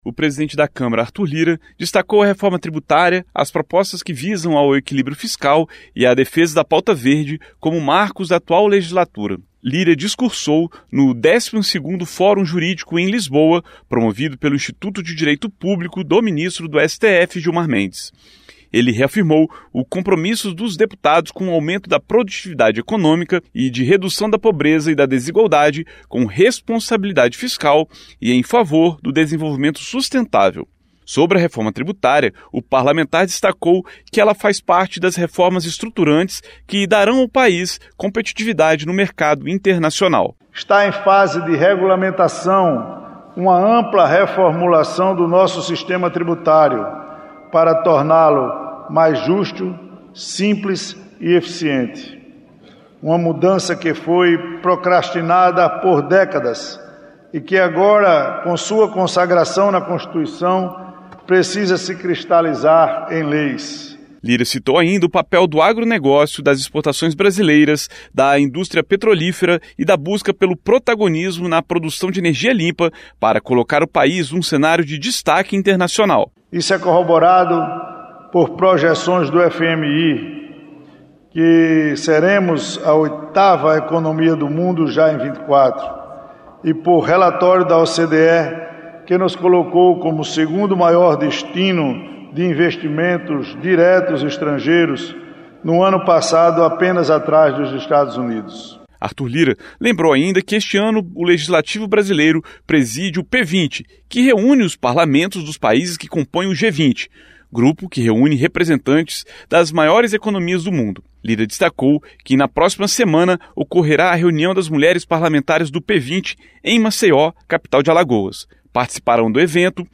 O PRESIDENTE DA CÂMARA, ARTHUR LIRA, PARTICIPOU DE EVENTO NO EXTERIOR E DESTACOU TEMAS IMPORTANTES DESSA LEGISLATURA.